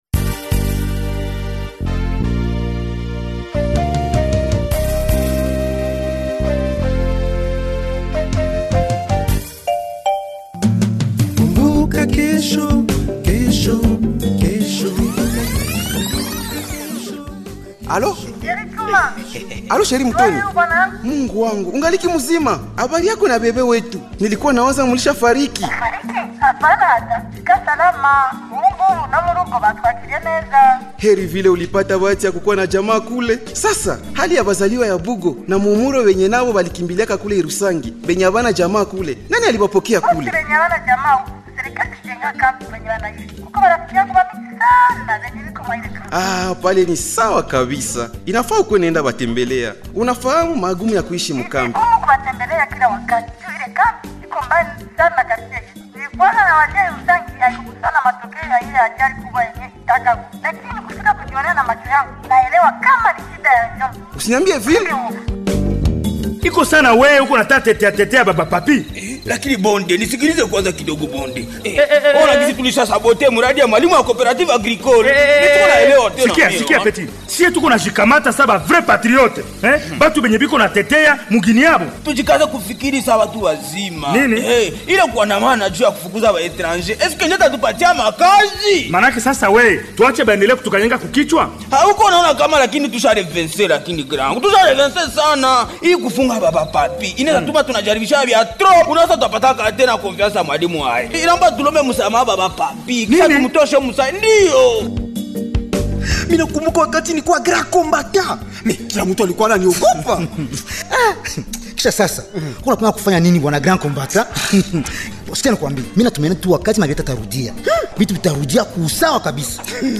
Voici le 984e numéro du feuilleton Kumbuka Kesho du 27 avril au 03 mai 2026